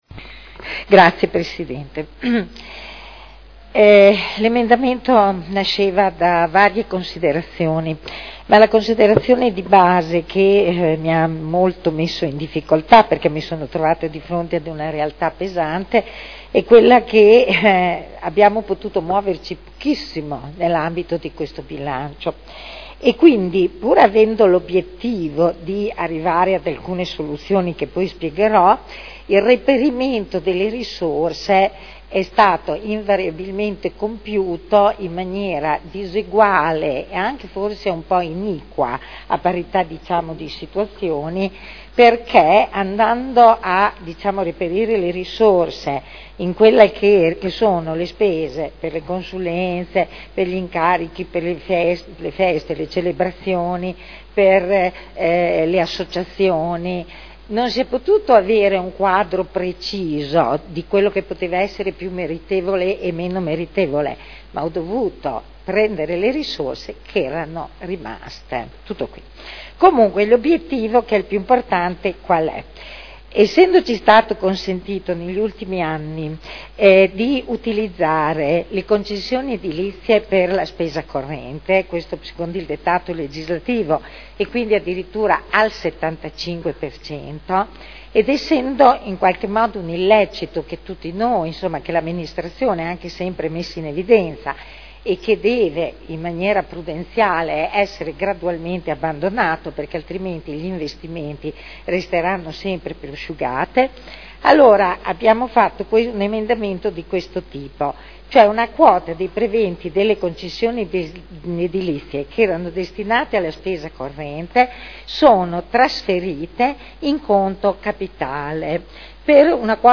Eugenia Rossi — Sito Audio Consiglio Comunale
Seduta dell'11 giugno Presentazione dell' emendamento del gruppo Italia dei valori